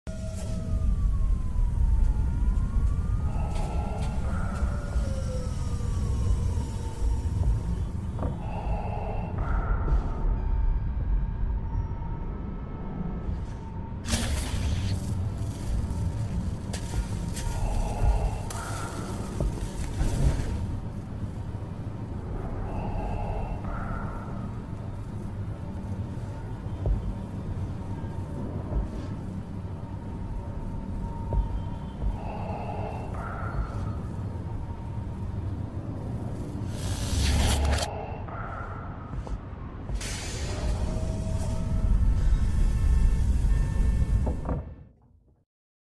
🤩 Sound design for 3D sound effects free download